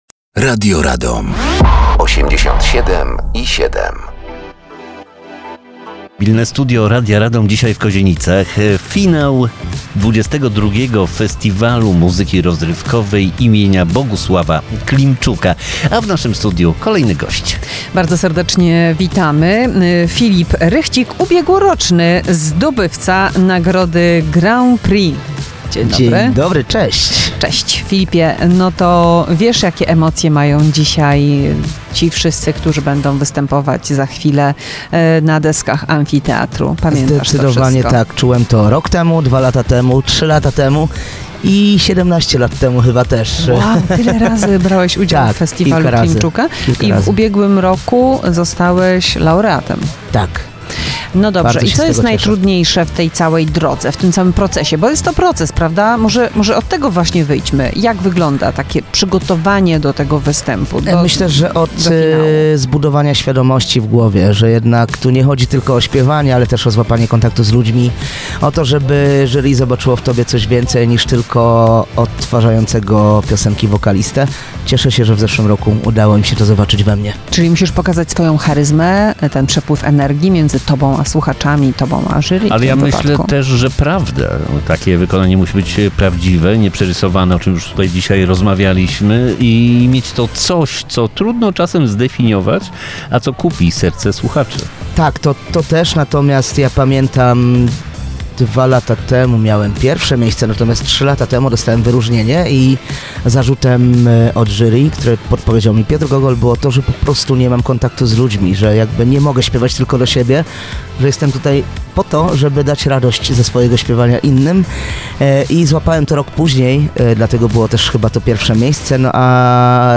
Mobilne Studio Radia Radom na XXII Festiwal Muzyki Rozrywkowej im. Bogusława Klimczuka.